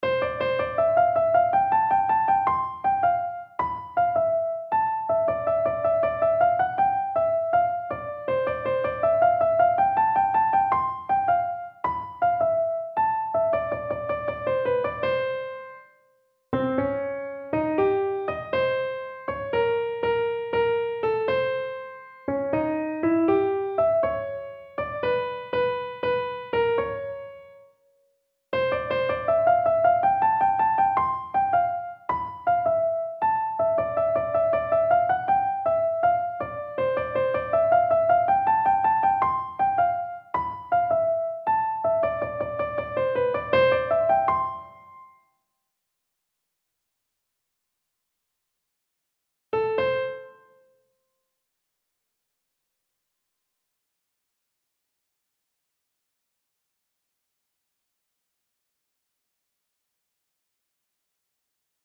Инструментальное произведение, возможно, баркарола, с грампластинки, примерно 60-х годов, синяя этикетка (не гарантировано). Подобрано по памяти, вероятны ошибки.
barkarola.mp3